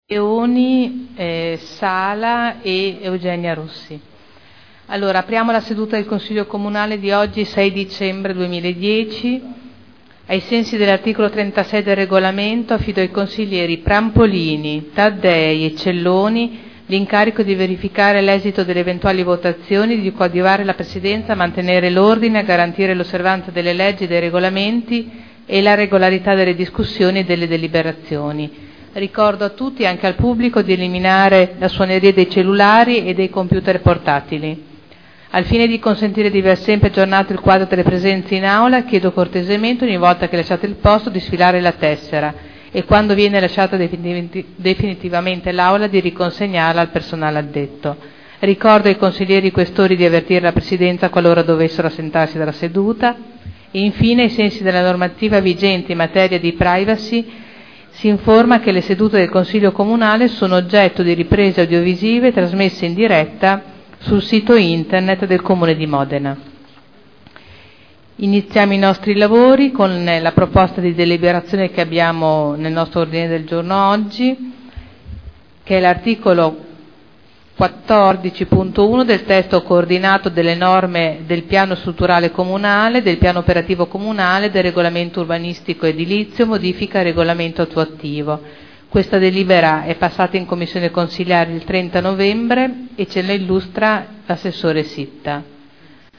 Il Presidente Caterina Liotti apre la seduta del Consiglio Comunale.